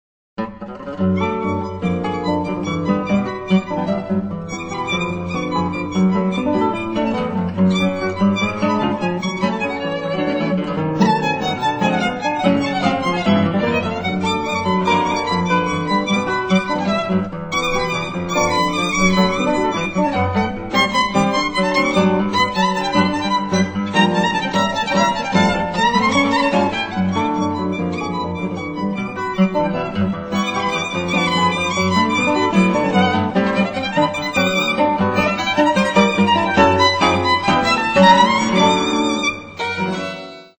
Moderne Tangos/ Tango-Atmosphäre